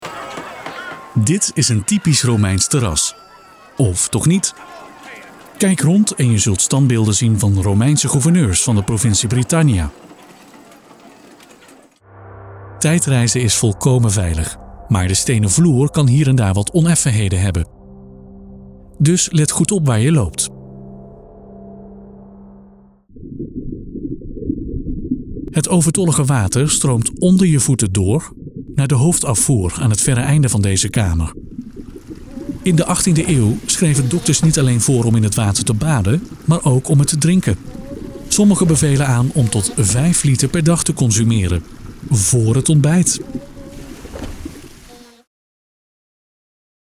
Reliable, Friendly, Corporate
Audio guide